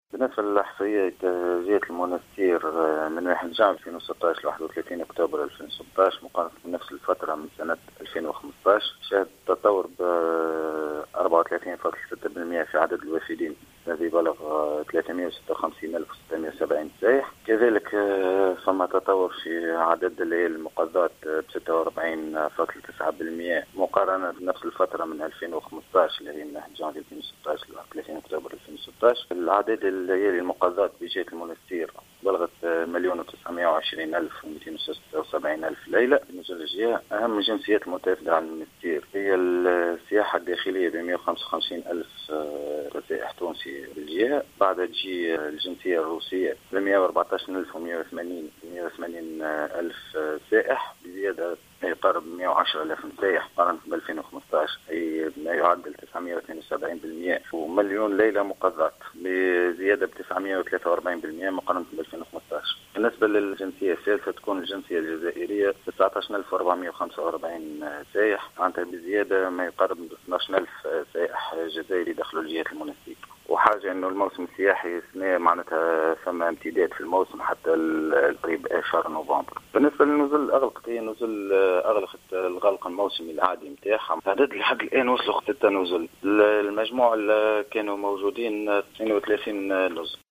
أكد المندوب الجهوي للسياحة بالمنستير فواز بن حليمة في تصريح للجوهرة "اف ام" اليوم الاثنين 7 نوفمبر 2016 أن هناك تطورا في المؤشرات السياحية من جانفي 2016 إلى موفى أكتوبر 2016 قدر ب34 بالمائة مقارنة بنفس الفترة من السنة الماضية.